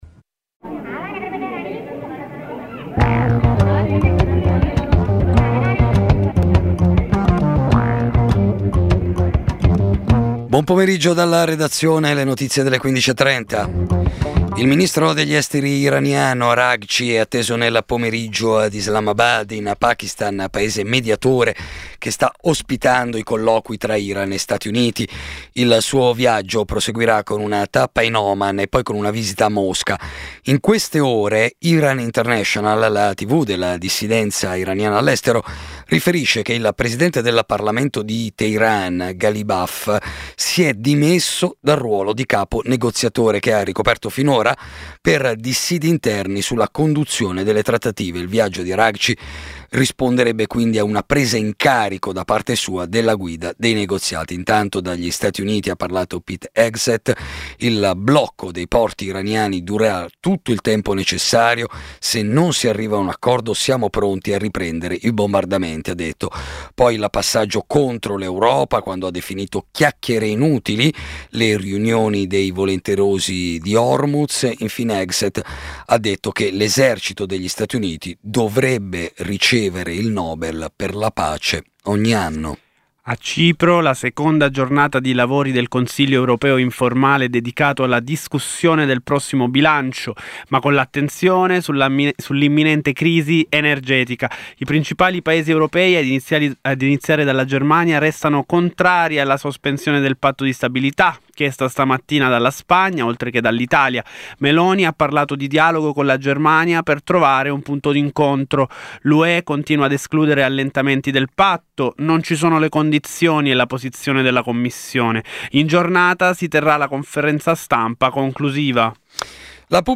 Edizione breve del notiziario di Radio Popolare.